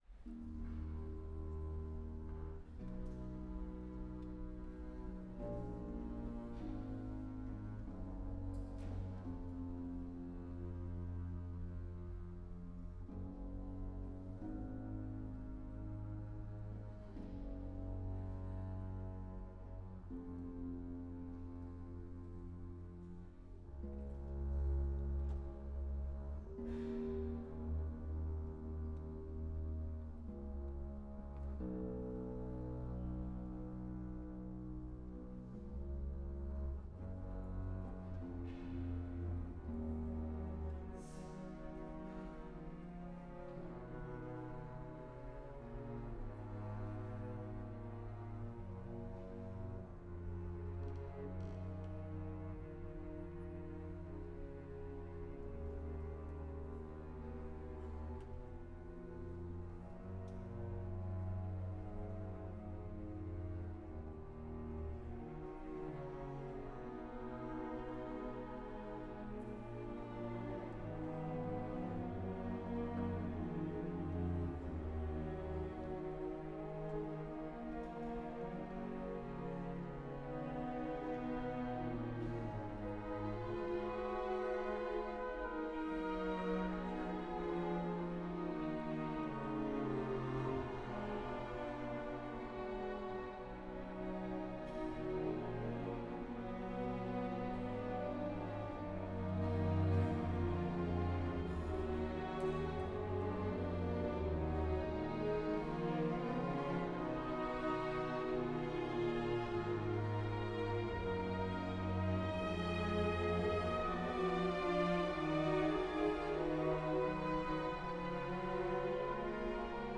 Μέγαρο Μουσικής Αθηνών, Δευτέρα 01 Απριλίου 2024
Εθνική Συμφωνική Ορχήστρα
ένα εμβληματικό έργο που αν και ακολουθεί τη μορφή της σχολικής Φούγκας